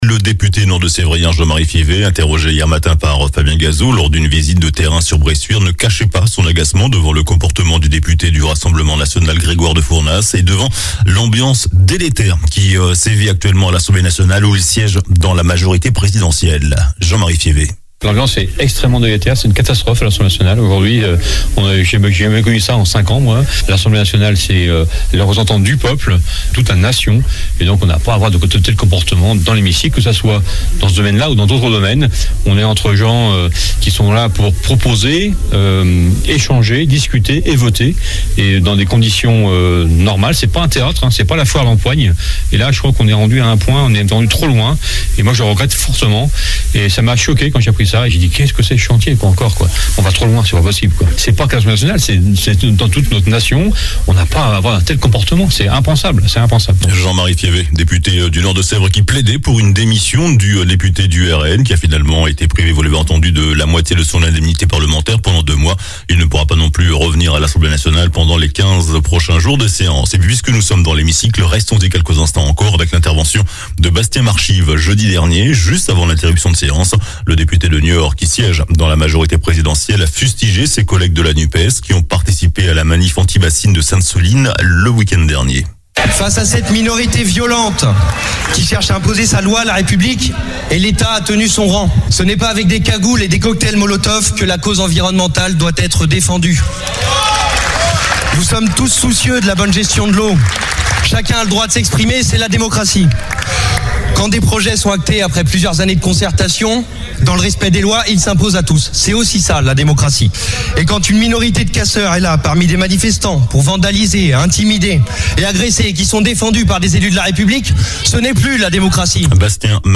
JOURNAL DU SAMEDI 05 NOVEMBRE